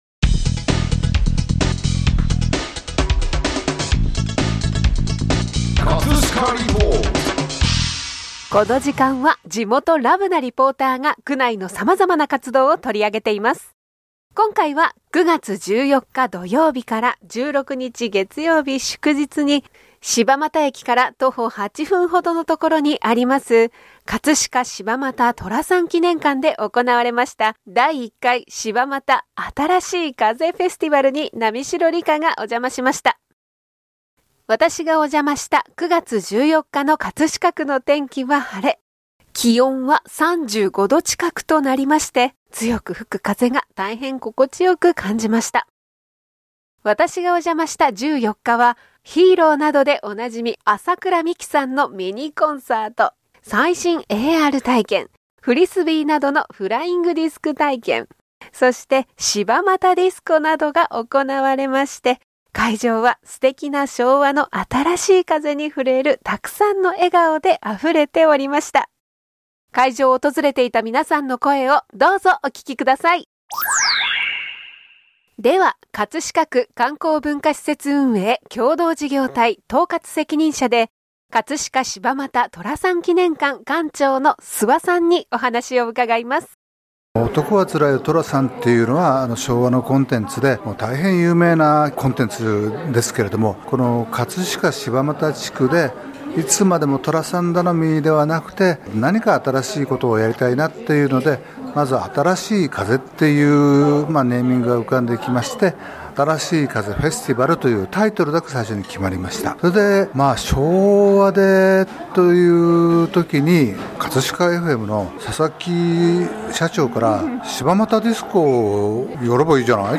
【葛飾リポート】
▼リポート音声